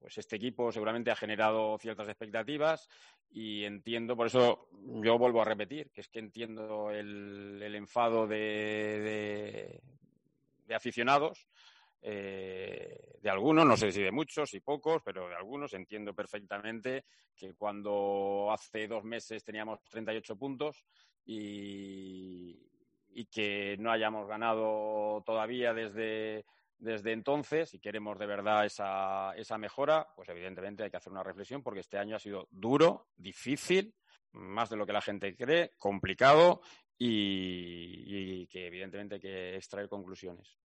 “No estamos nada contentos ninguno de cómo está acabando el equipo, es la gran verdad. Entiendo que nos quedemos con lo último y con las malas sensaciones de esta dinámica tan negativa de resultados. Me pongo en la piel del aficionado y pensaría en lo mismo”, dijo en la rueda de prensa previa al partido.